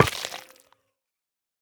Minecraft Version Minecraft Version 1.21.5 Latest Release | Latest Snapshot 1.21.5 / assets / minecraft / sounds / block / sculk_catalyst / break10.ogg Compare With Compare With Latest Release | Latest Snapshot
break10.ogg